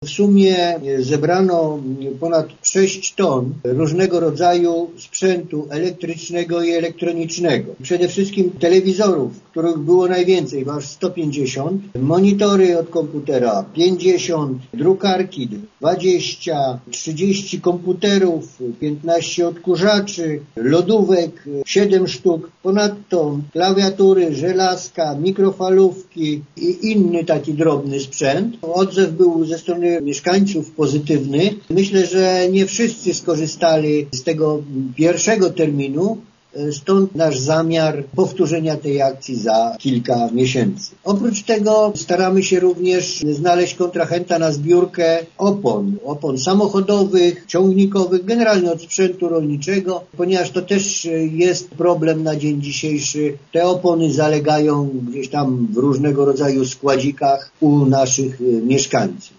– Efekty są na tyle obiecujące, że już przygotowujemy kolejną taka akcję – zapowiada wójt Franciszek Kwiecień: